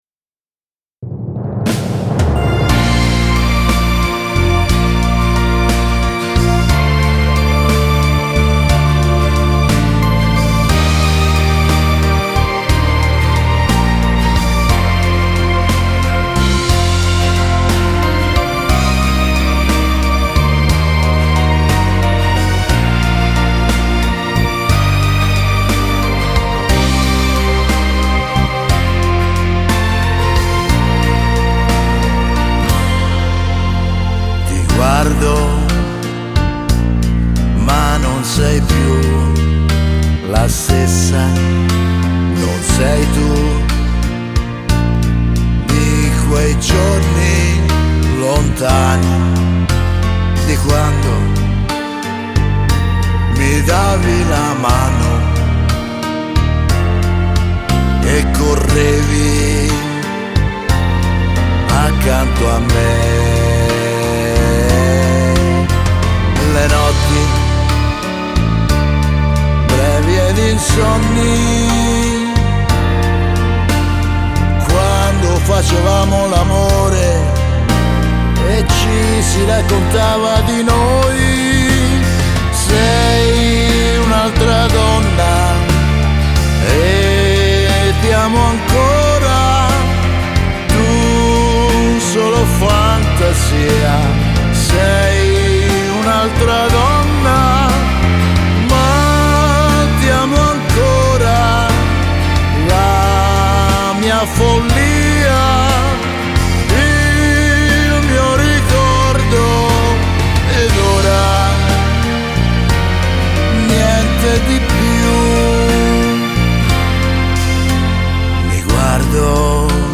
(Terzinato)